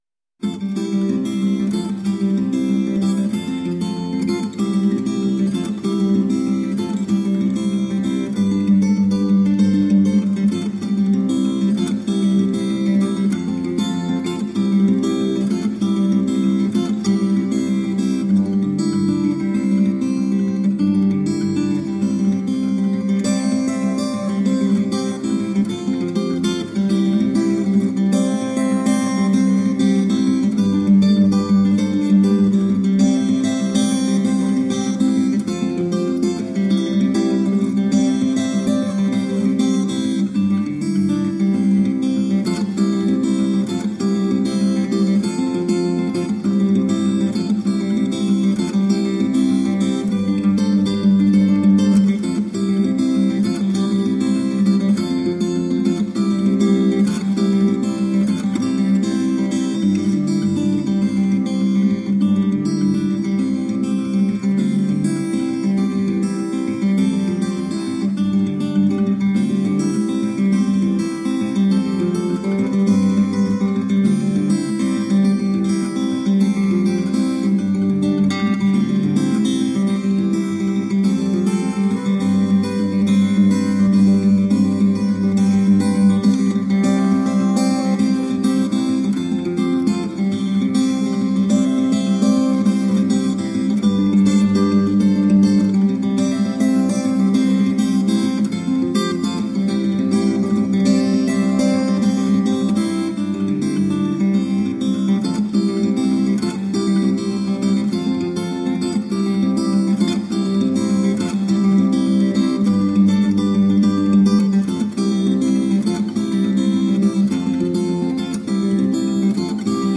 Acoustic new age and jazz guitar..
solo acoustic guitar pieces